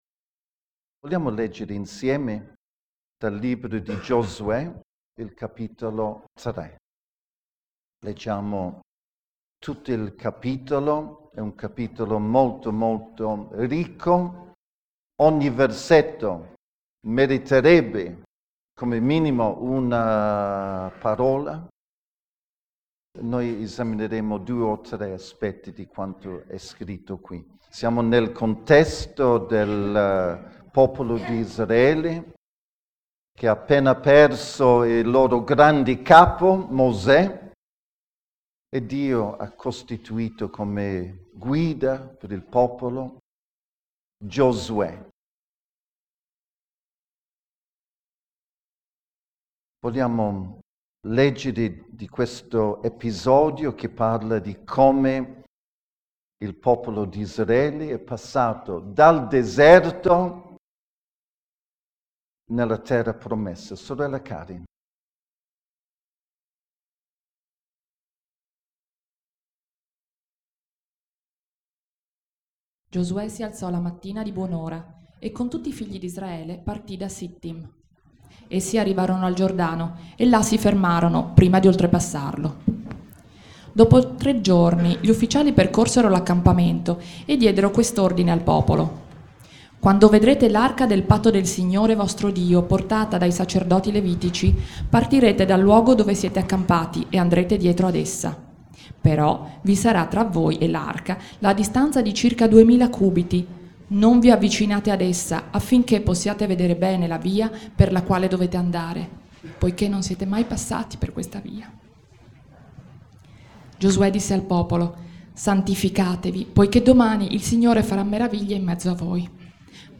Predicazione 05 maggio 2013 - Ordine e fede